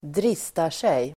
Uttal: [²dr'is:tar_sej]